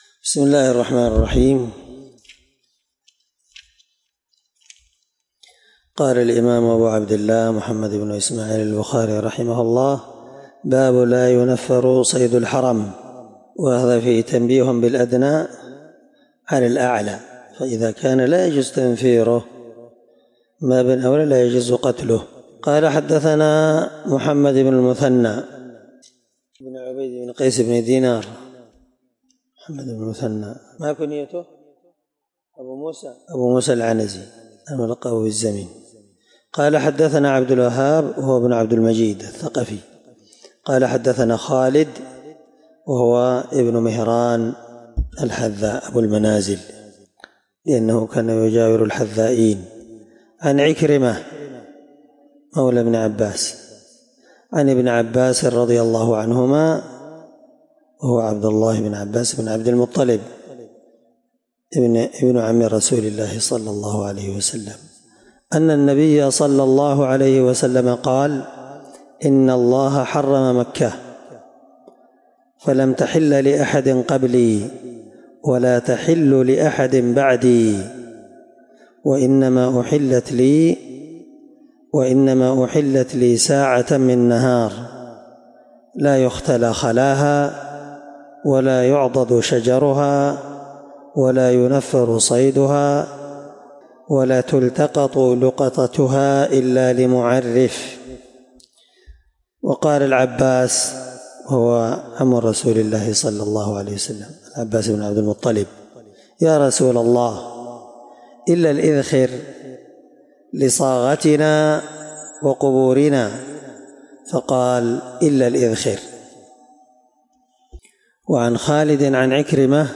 الدرس 9من شرح كتاب جزاء الصيد حديث رقم(1833 )من صحيح البخاري